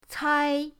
cai1.mp3